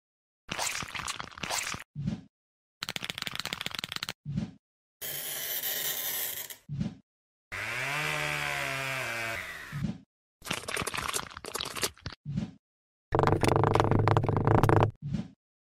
Argentina’s GOAT Star Mp3 Sound Effect Messi ASMR, Argentina’s GOAT Star Whispers!